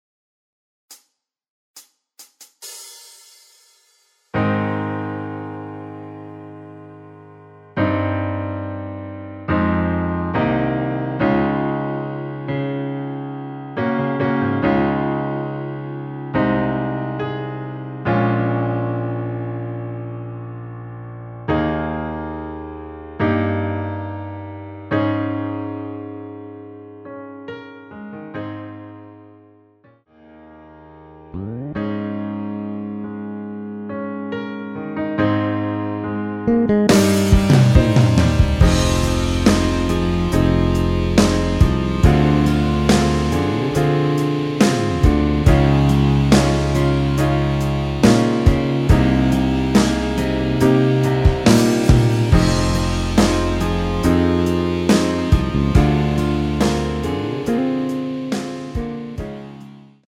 전주없이 노래가 시작되는곡이라 카운트 만들어놓았습니다.
Ab
앞부분30초, 뒷부분30초씩 편집해서 올려 드리고 있습니다.
중간에 음이 끈어지고 다시 나오는 이유는